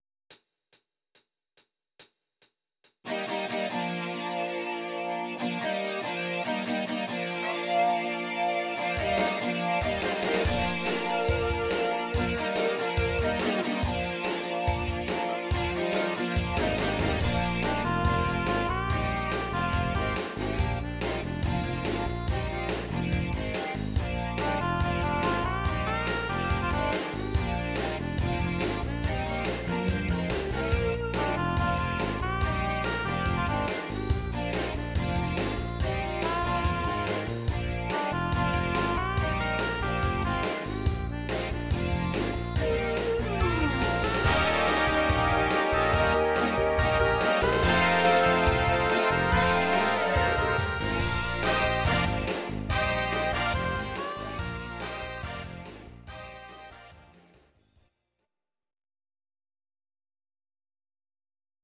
Audio Recordings based on Midi-files
Pop, Rock, Duets, 1990s